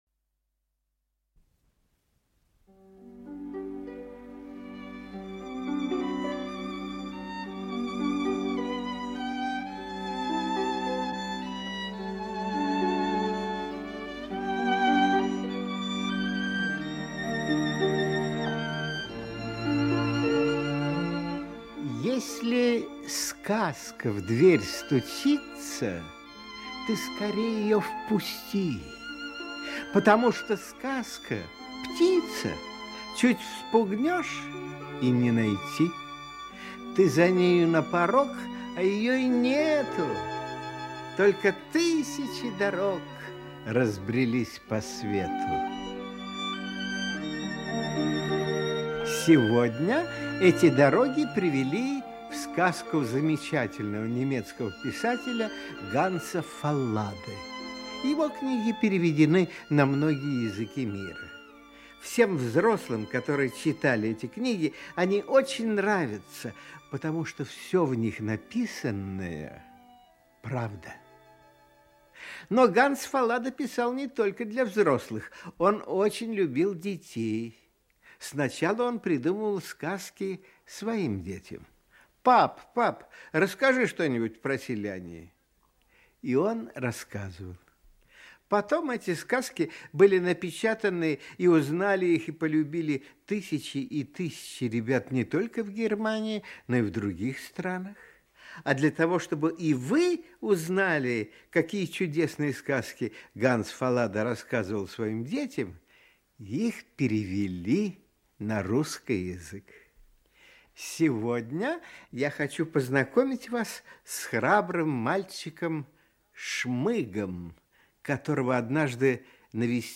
Аудиокнига Страшные гости | Библиотека аудиокниг
Aудиокнига Страшные гости Автор Ганс Фаллада Читает аудиокнигу Актерский коллектив.